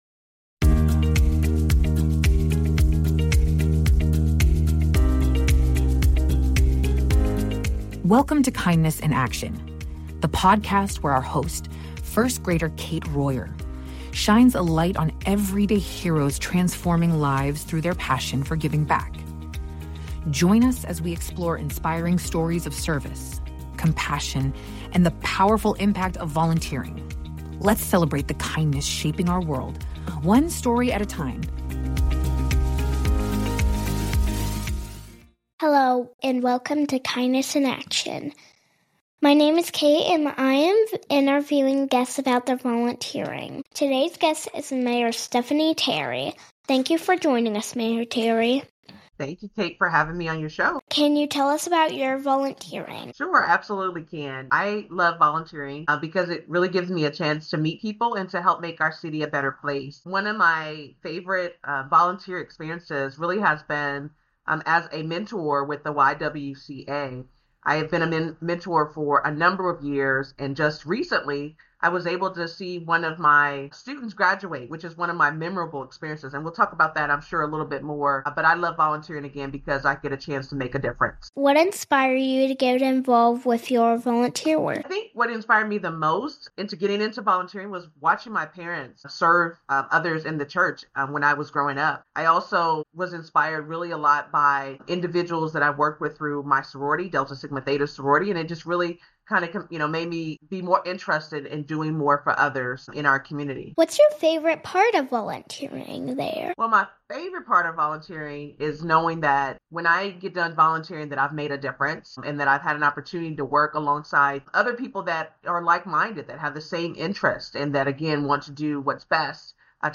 talks with Evansville's Mayor, Stephanie Terry, about her experiences with volunteering and her role as a community leader. Mayor Terry shares her passion for mentoring young girls through the YWCA, the impact of small acts of kindness, and her journey as the mayor of Evansville.